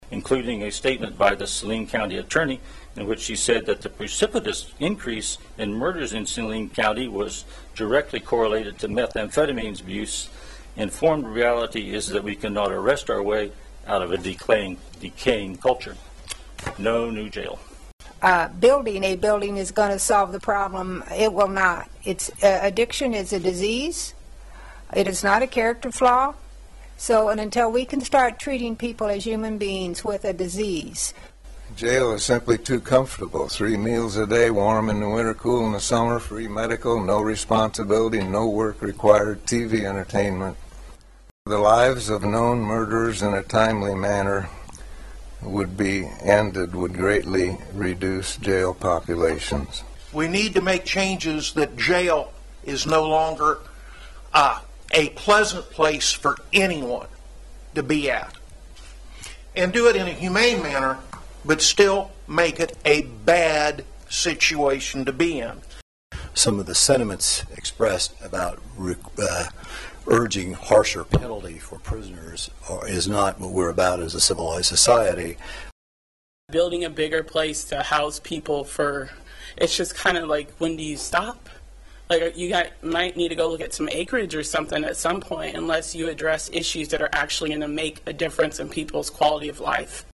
The Saline County Commission hosted a town hall meeting to discuss the issue.
Nearly a dozen people spoke. Nearly all were against building a new jail in a new location.
Jail-Town-Hall-Comments.mp3